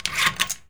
door_lock_slide_03.wav